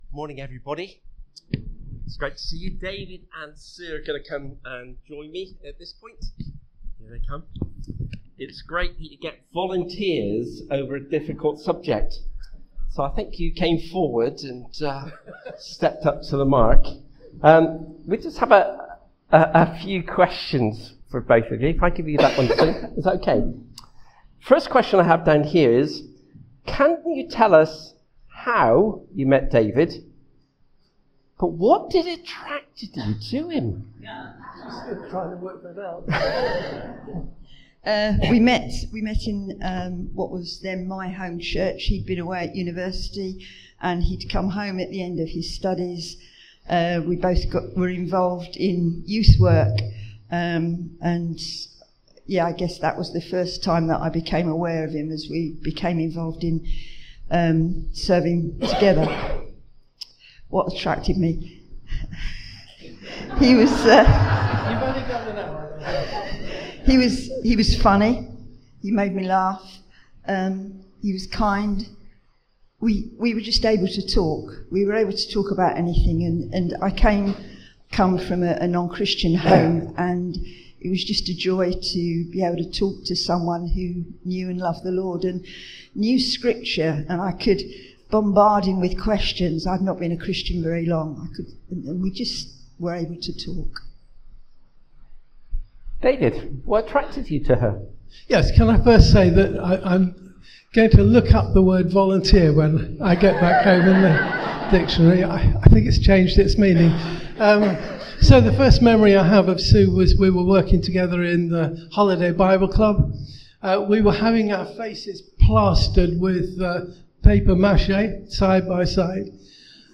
This sermon was preached on Sunday 22nd June 2025 at a CBC Priory Street.